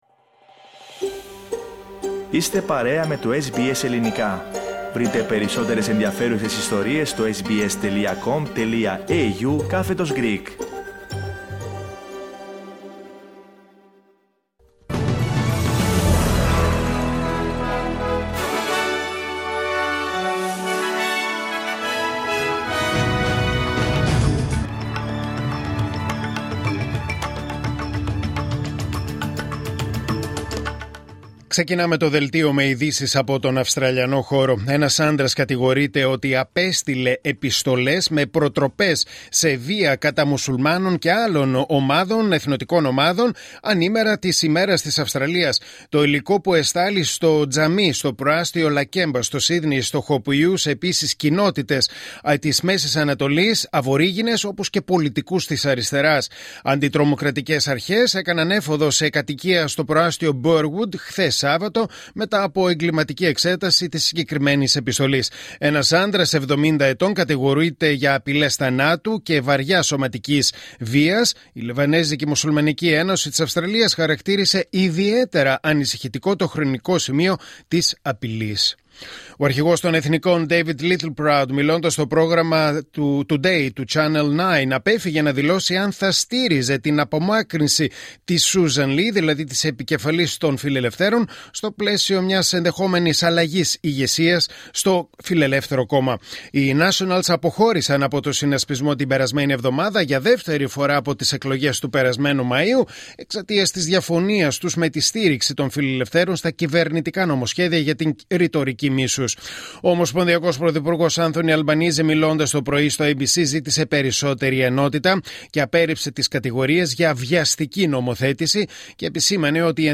Δελτίο Ειδήσεων Κυριακή 25 Ιανουαρίου 2026